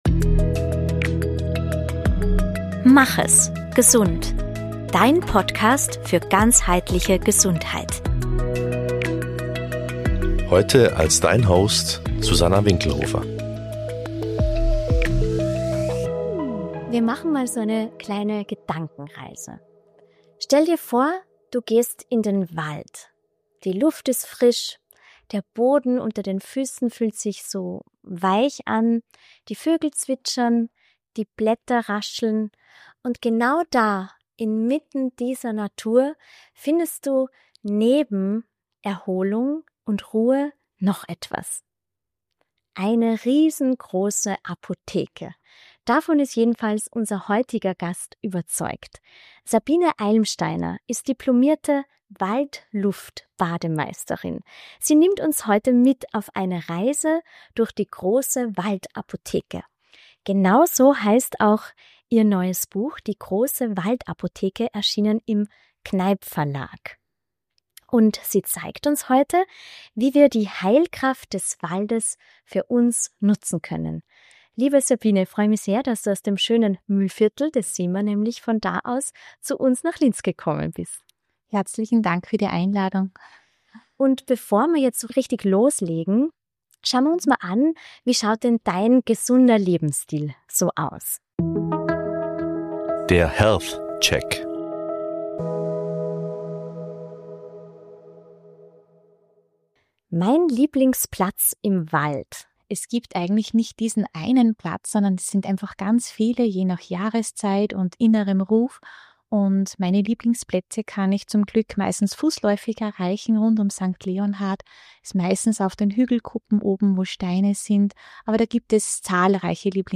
Ein Gespräch über Natur, Gesundheit, kindliche Neugier – und die Magie, die zwischen Wurzeln, Flechten und Lichtungen wohnt.